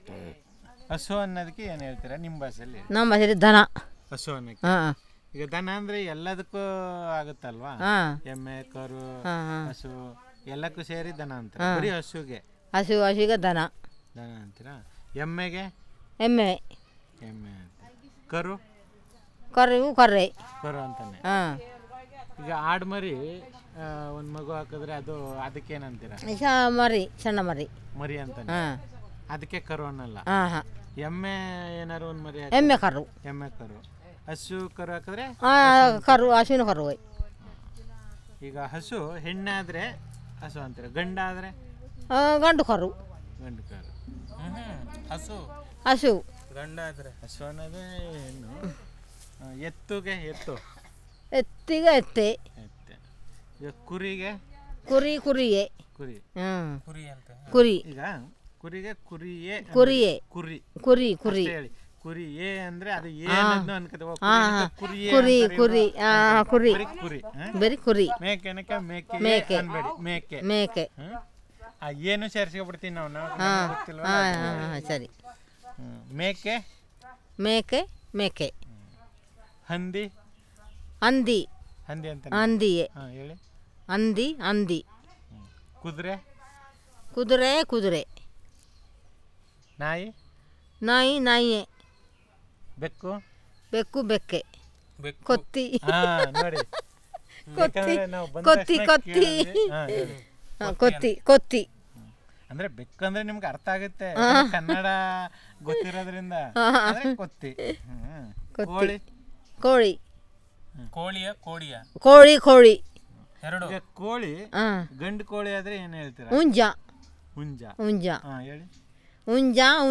Elicitation of words about domestic animals and related